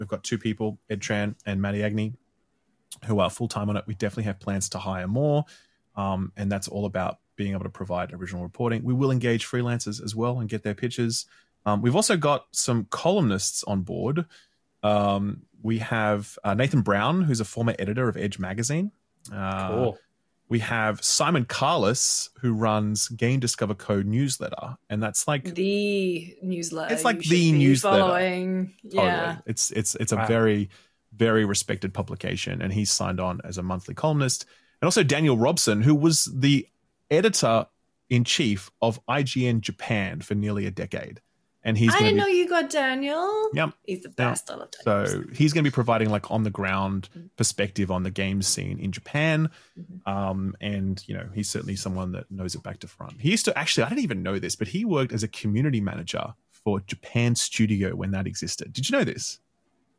Interview: Ghost of Yotei Directors share their ambitious plans for the sequel | FPS Podcast #74 by Skill Up